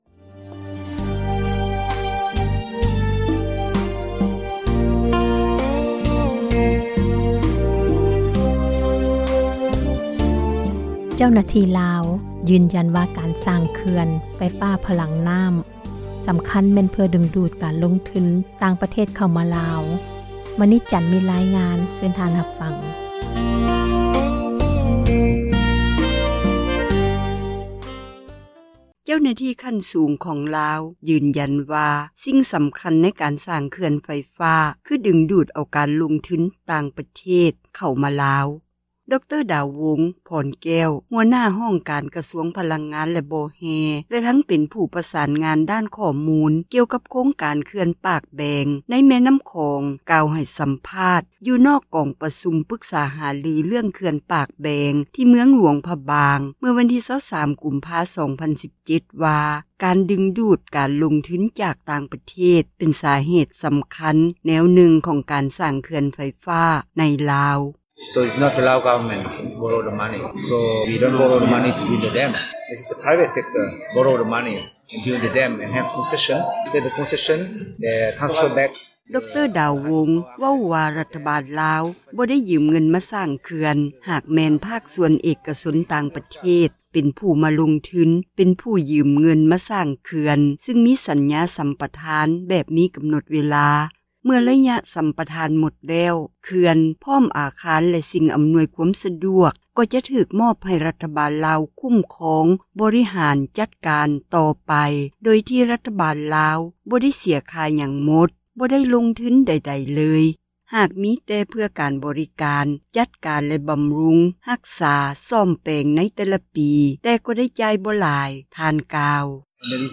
ລາວສ້າງເຂື່ອນດຶງດູດ ນັກລົງທຶນ — ຂ່າວລາວ ວິທຍຸເອເຊັຽເສຣີ ພາສາລາວ